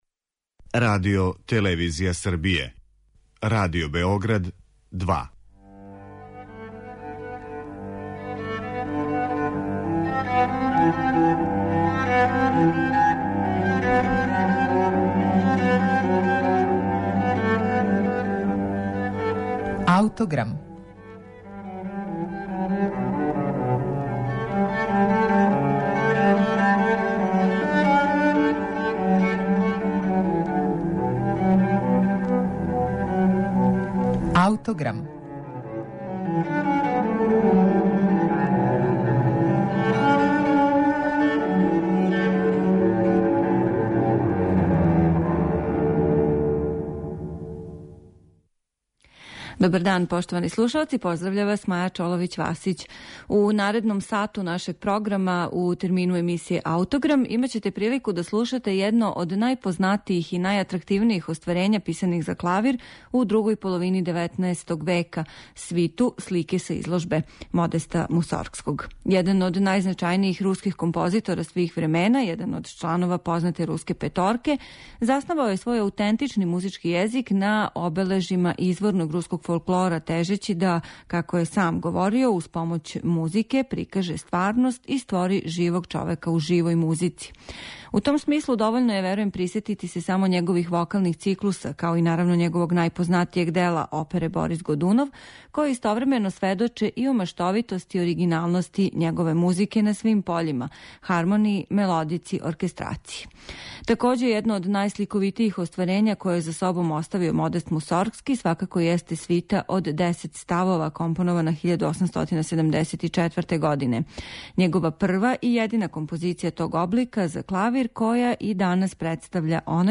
Слушаћете једно од најоригиналнијих дела клавирске литературе друге половине 19. века - Слике сa изложбе Модеста Мусоргског, у изузетној интерпетацији Свјатослава Рихтера.